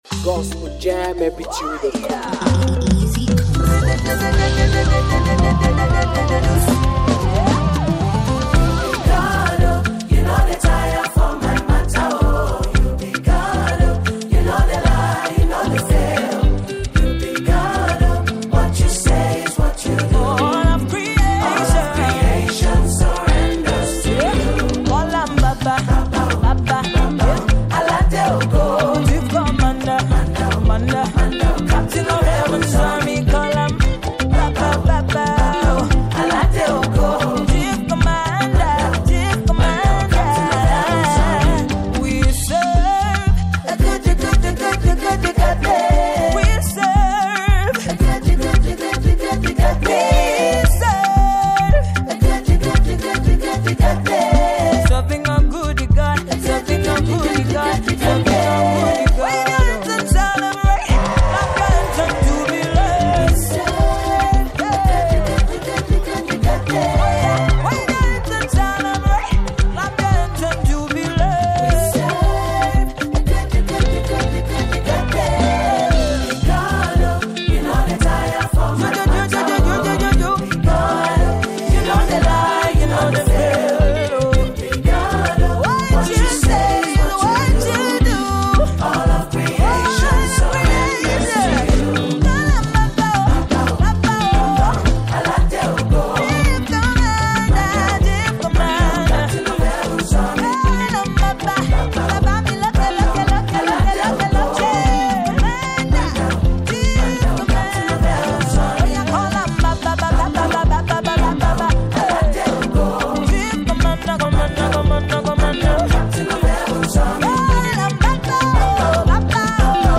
a powerful gospel anthem